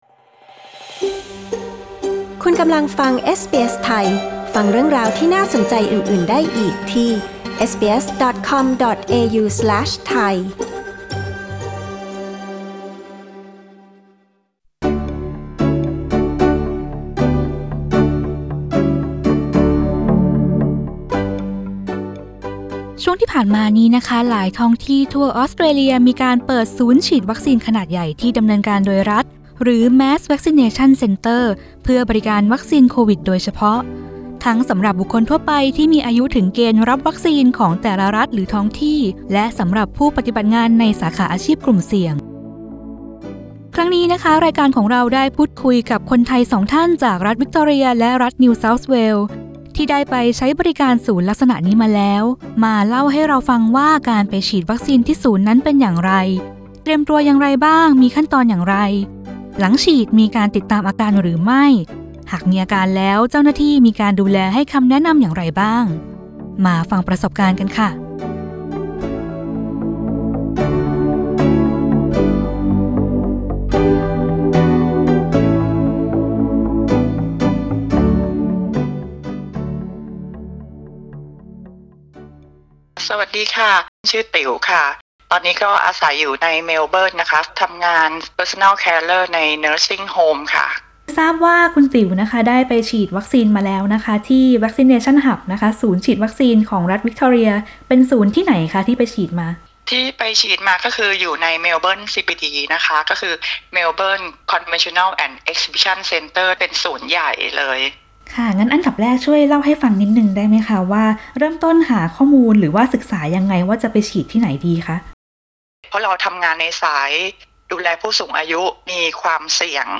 หลายท้องที่ในออสเตรเลียเปิดศูนย์ฉีดวัคซีนขนาดใหญ่ที่ดำเนินการโดยรัฐ (Mass Vaccination Centre) ให้บริการวัคซีนโควิดทั้งสำหรับบุคคลทั่วไปที่มีสิทธิ์ตามเกณฑ์อายุและผู้ปฏิบัติงานสาขาอาชีพกลุ่มเสี่ยง คนไทยสองท่านจากรัฐวิกตอเรียและรัฐนิวเซาท์เวลส์มาร่วมเล่าประสบการณ์ฉีดวัคซีนที่ศูนย์ ทั้งการเตรียมตัว ขั้นตอนตั้งแต่ต้นจนจบ ตลอดจนการติดตามอาการหลังฉีดและดูแลให้คำแนะนำโดยเจ้าหน้าที่